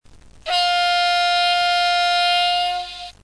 Estos sonidos se han grabado directamente del decoder o módulo una vez instalado en la locomotora.
250silbato.mp3